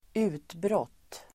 Uttal: [²'u:tbråt:]